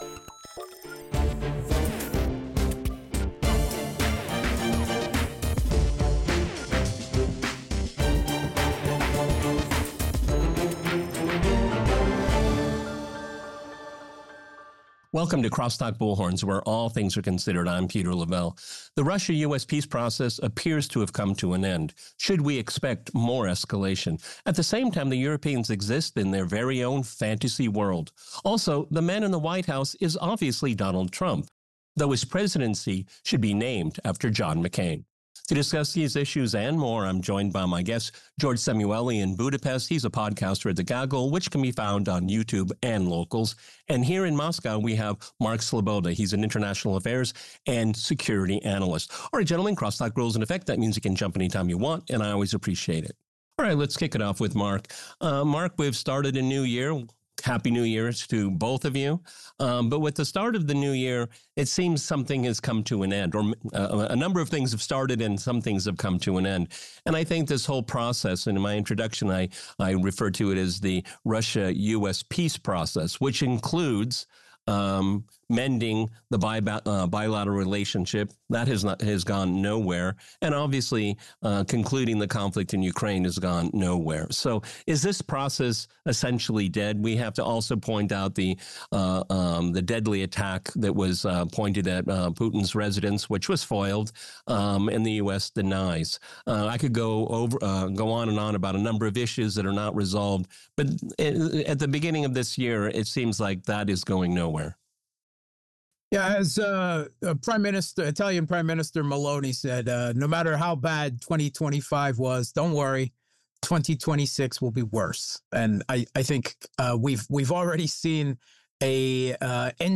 Highlights: President Obama delivers 2014 State of the Union